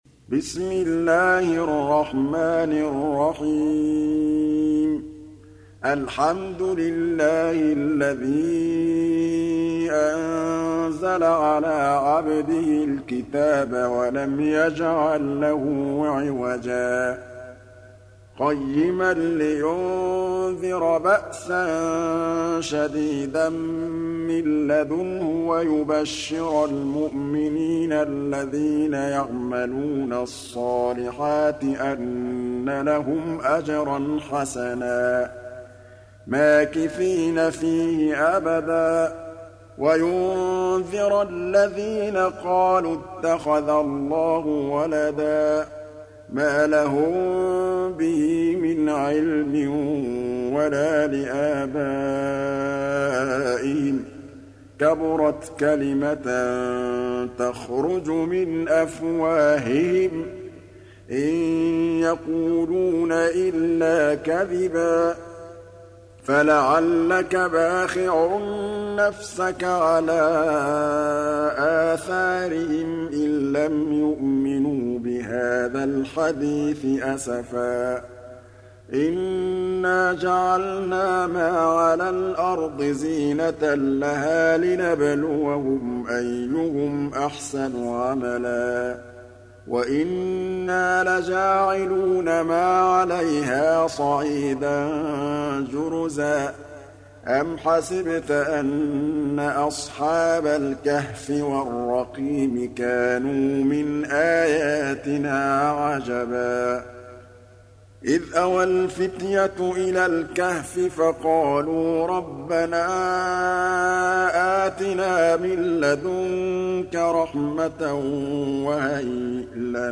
Surah Repeating تكرار السورة Download Surah حمّل السورة Reciting Murattalah Audio for 18. Surah Al-Kahf سورة الكهف N.B *Surah Includes Al-Basmalah Reciters Sequents تتابع التلاوات Reciters Repeats تكرار التلاوات